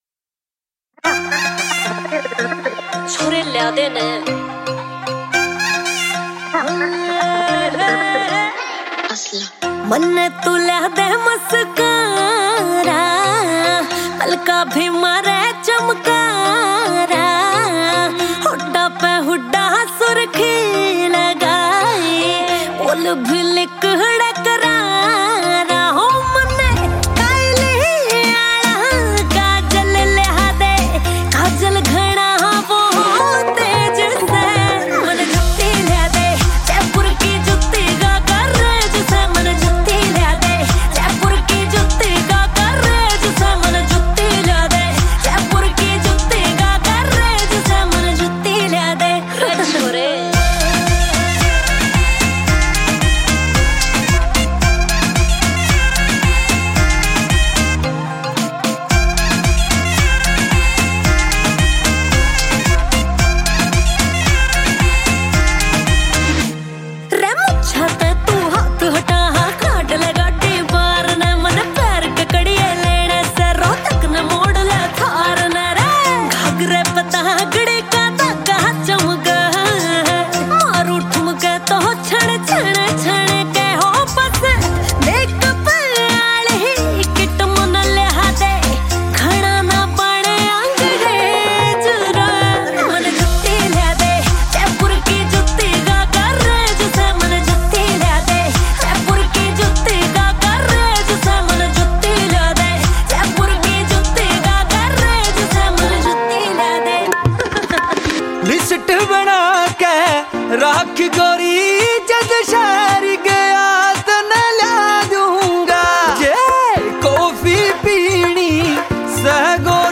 Haryanvi